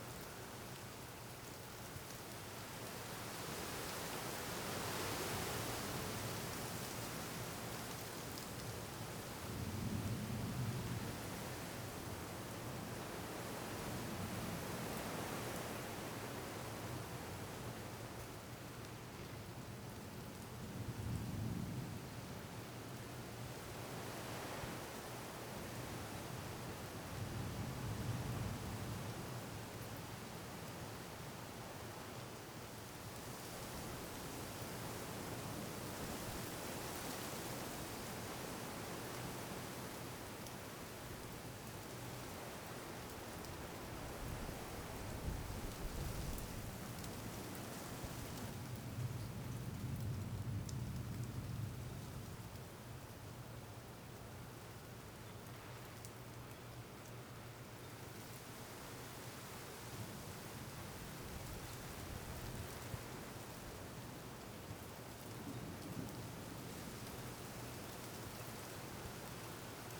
Weather Evening Light Rain Thunder Rustling Trees ST450 01_ambiX.wav